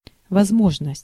Ääntäminen
US : IPA : /ə.ˈbɪl.ə.ti/